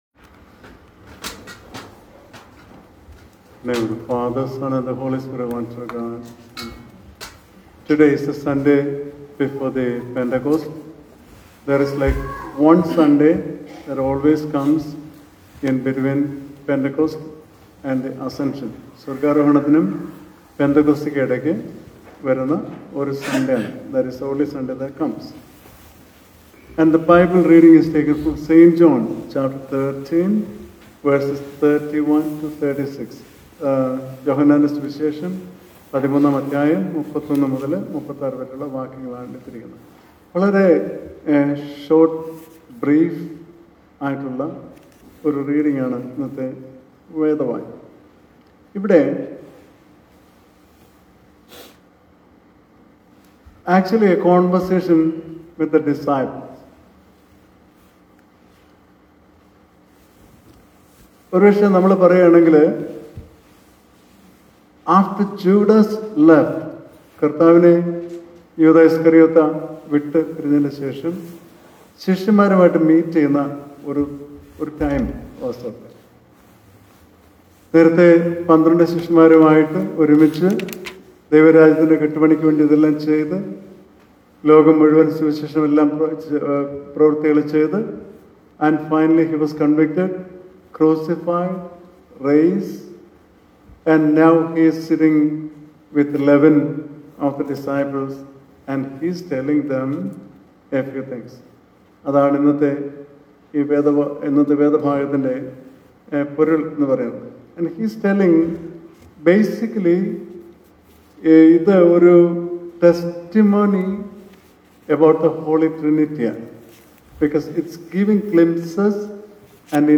Sermon – June 1-2025 – St. John 13: 31-36 - St Mary's Indian Orthodox Church of Rockland